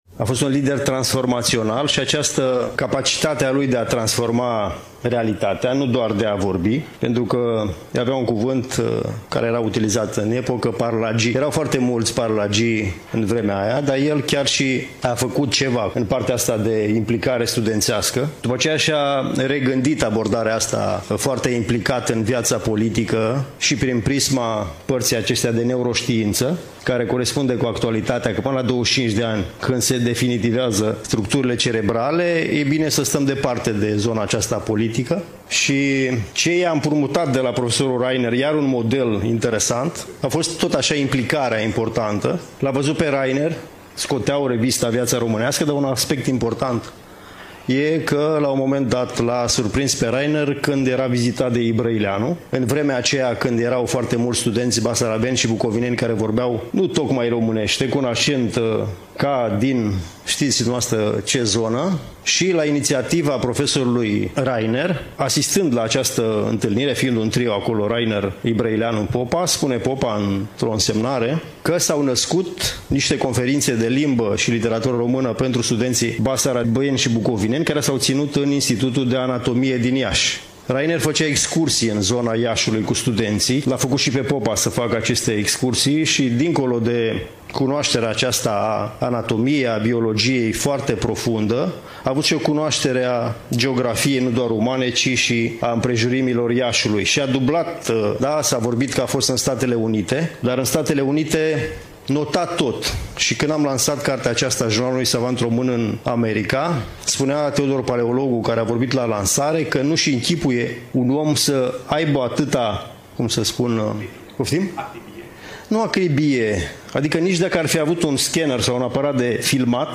Prezentarea lucrării s-a desfășurat în incinta Muzeului Municipal „Regina Maria”, str. Zmeu, nr. 3.